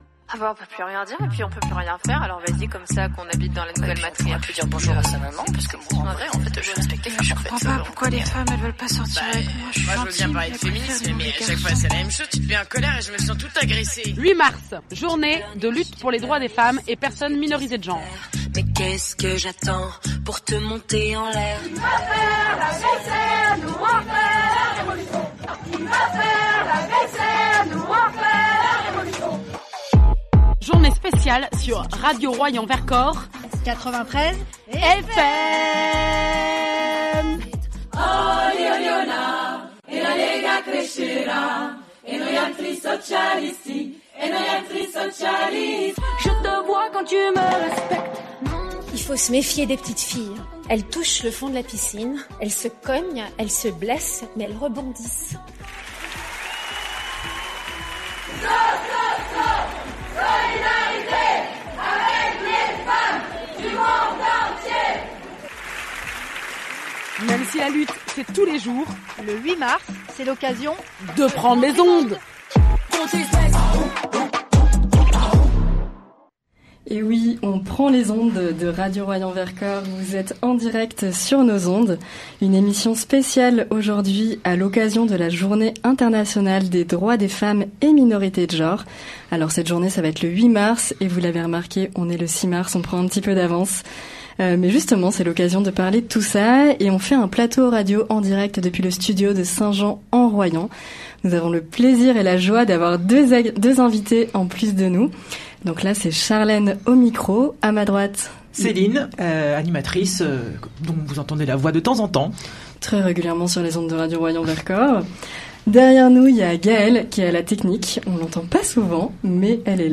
8 mars, journée du droit des femmes (émission en direct)
Une émission en direct à l’occasion de la journée internationale du droit des femmes !